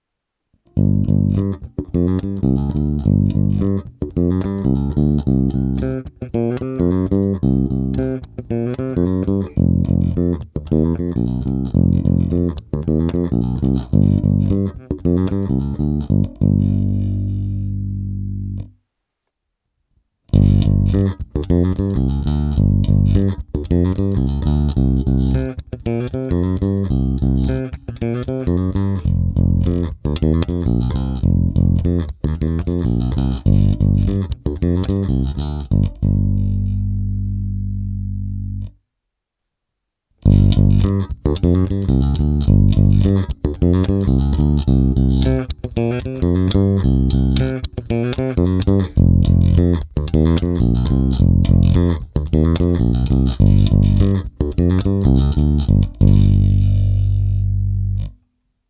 Není-li uvedeno jinak, ukázky jsou provedeny rovnou do zvukové karty a jen normalizovány. Hráno vždy nad snímačem.
Basy a výšky naplno